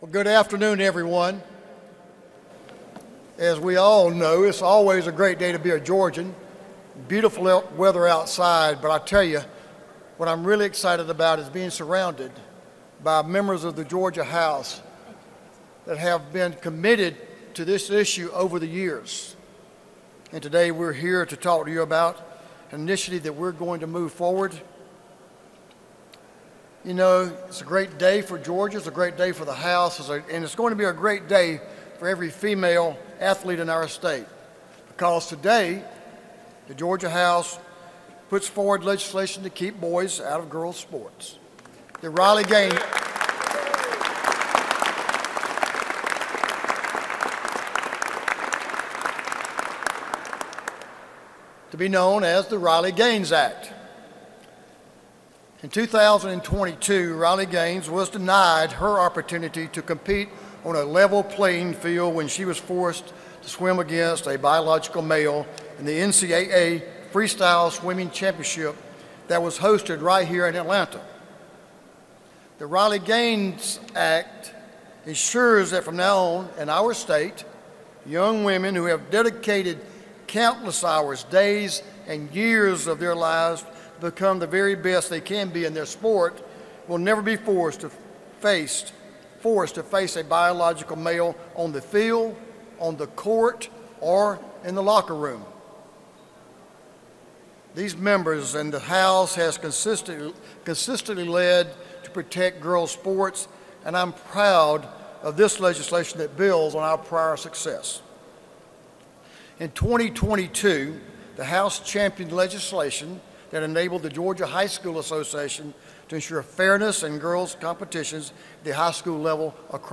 Georgia House Speaker Jon Burns, R-Newington, speaks during a Tuesday, Feb. 4, 2025, news conference.